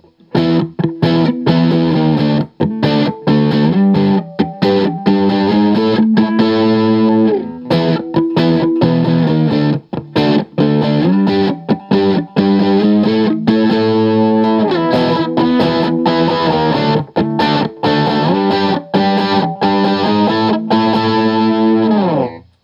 Every sound sample cycles from the neck pickup, to both, to the bridge pickup.
JCM800
Barre Chords
[/dropshadowbox]For these recordings I used my normal Axe-FX Ultra setup through the QSC K12 speaker recorded into my trusty Olympus LS-10.